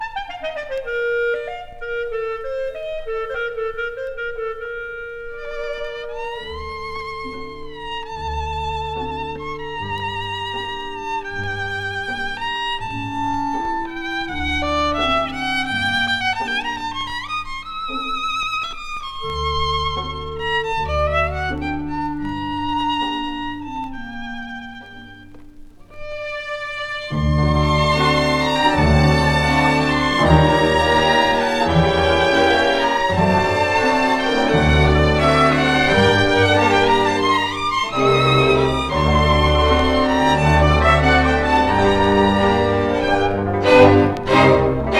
World, Folk, Romani　Hungary　12inchレコード　33rpm　Stereo